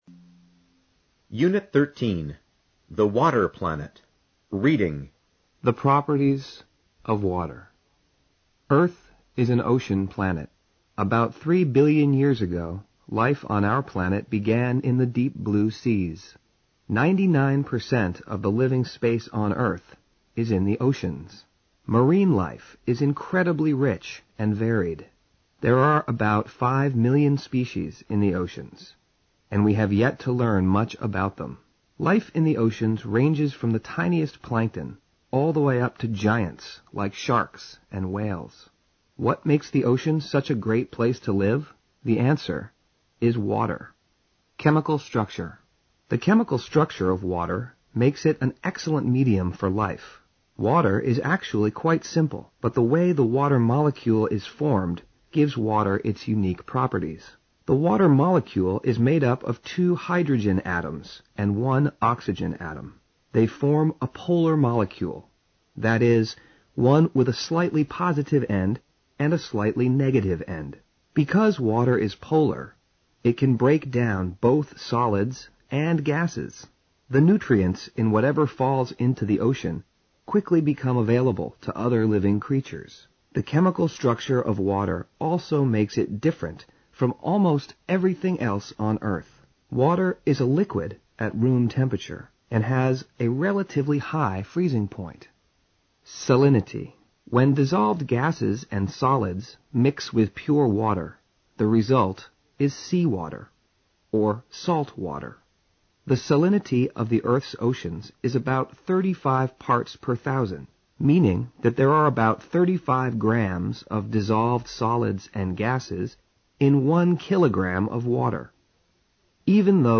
高中英语第二册课本朗读13-d 听力文件下载—在线英语听力室